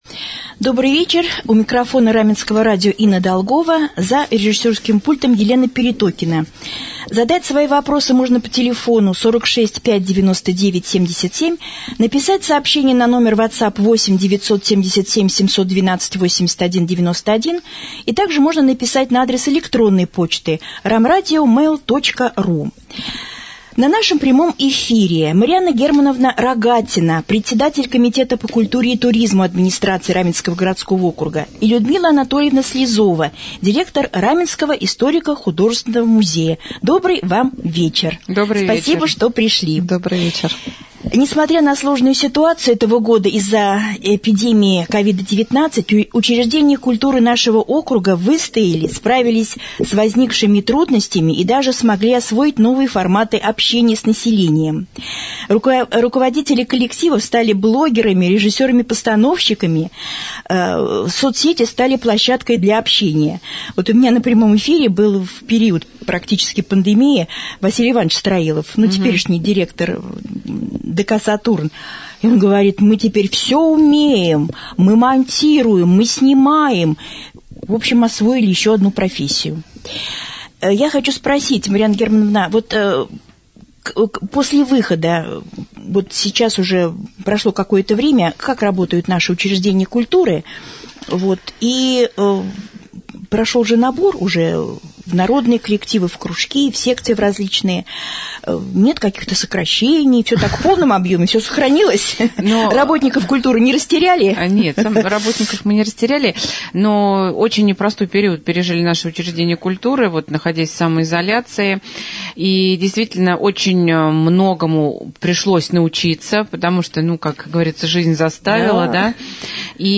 prjamoj-jefir.mp3